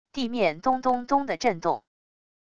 地面咚咚咚的震动wav音频